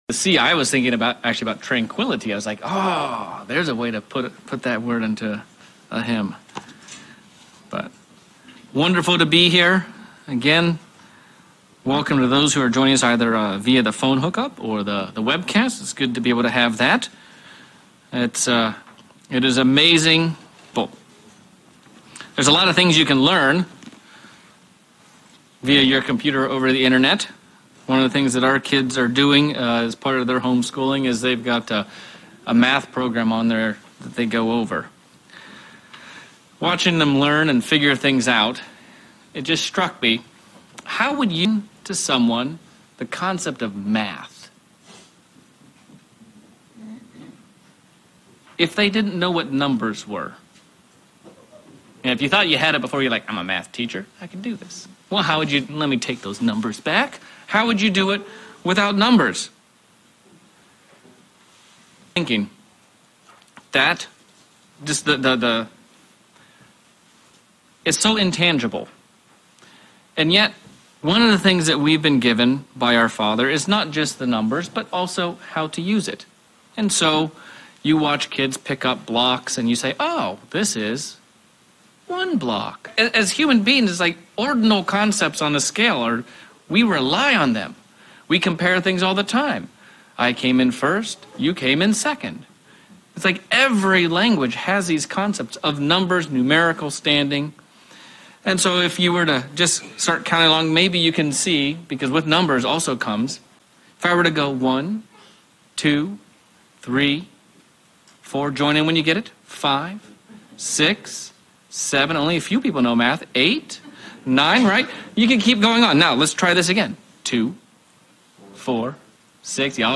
Sermon looking at how and why we count Pentecost the way we do. God has given us a special Holy Day that we have to count, because if we do not count, we forget.